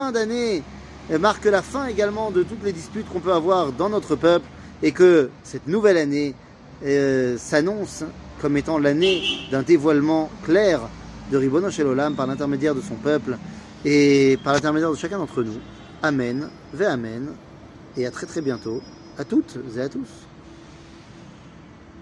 שיעור מ 06 ספטמבר 2023